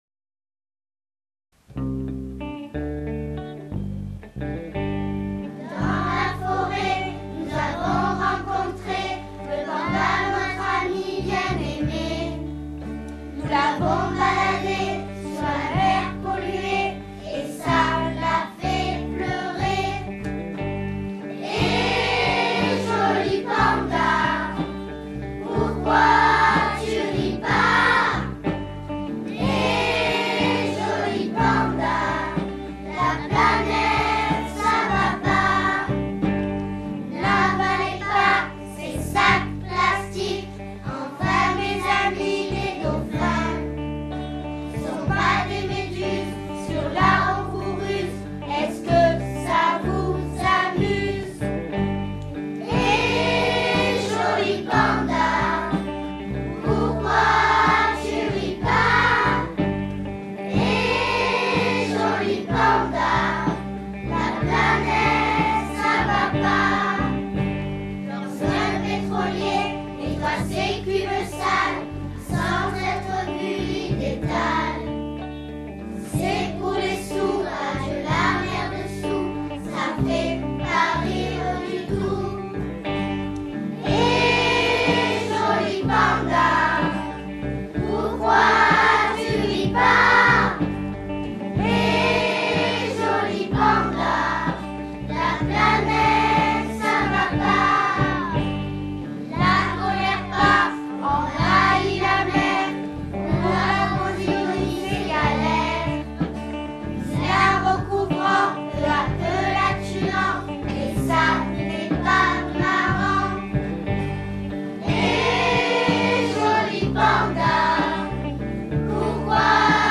Chant : Hé le Panda! (version mer)